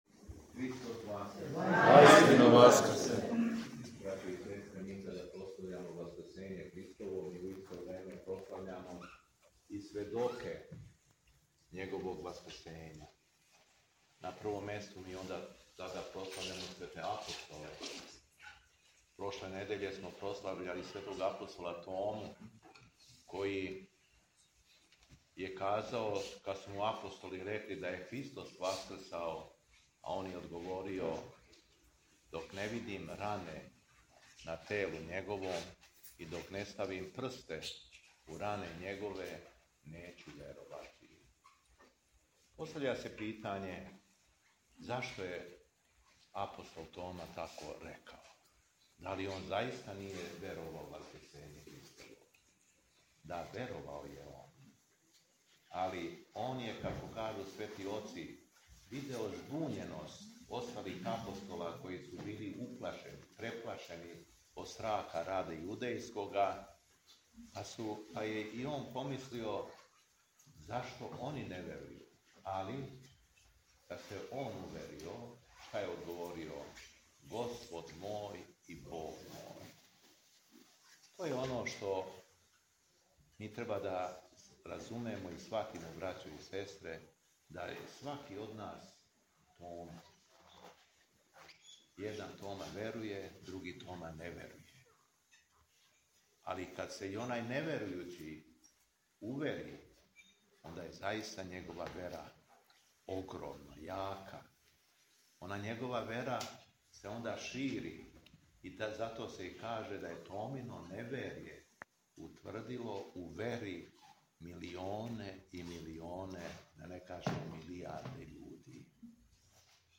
У суботу, 3. маја 2025. године, Његово Високопреосвештенство Архиепископ крагујевачки и Митрополит шумадијски служио је празнично бденије у храму Светих жена Мироносица на Варошком гробљу у Крагујевцу.
Беседа Његовог Високопреосвештенства Митрополита шумадијског г. Јована